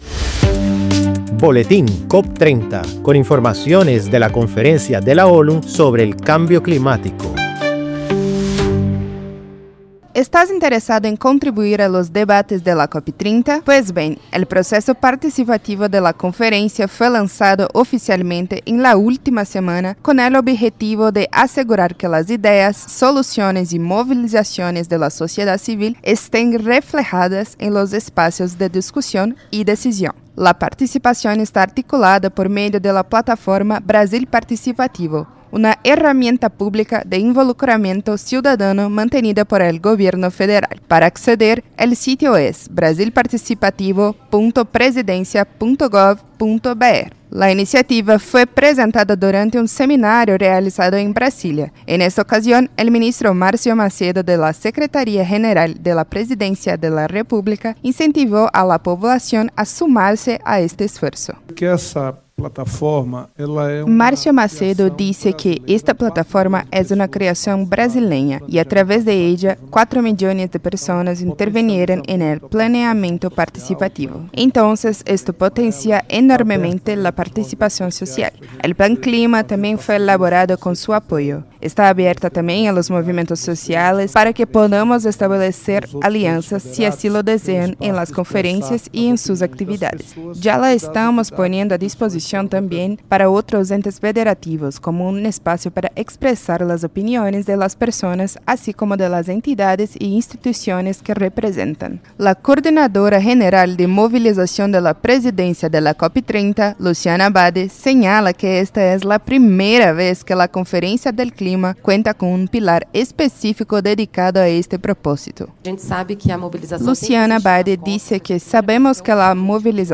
La participación está articulada por medio de la plataforma Brasil Participativo. El proceso está compuesto por diferentes frentes de acción, como la presentación de soluciones y el mapeo de actividades. Escucha el reportaje y conoce más.